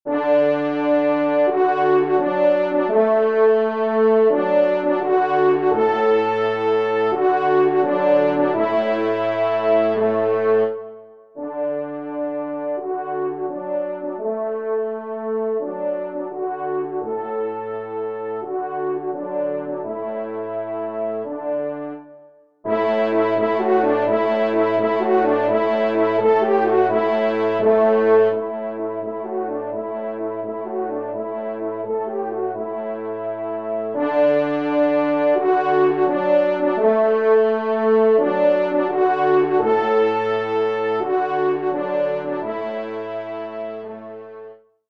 Genre :  Divertissement pour Trompes ou Cors en Ré
Basses